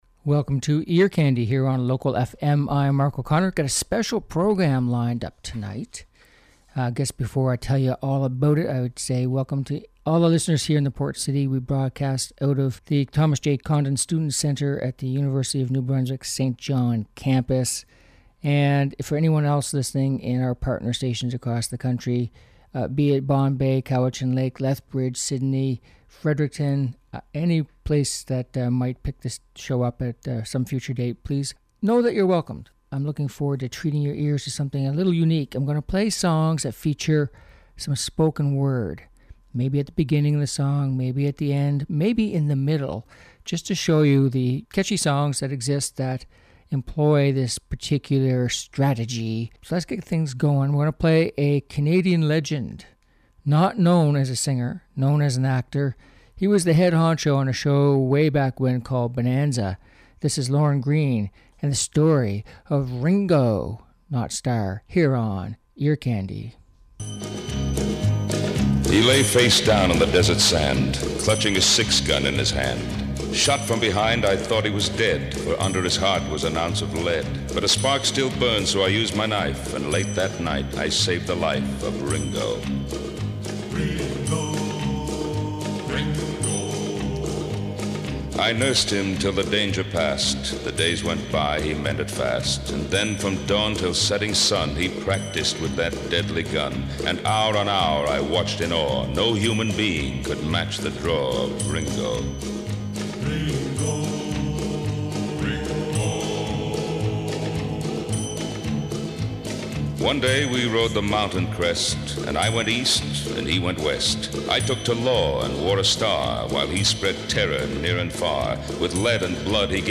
Upbeat Pop and Rock Songs - Songs With Spoken Word Special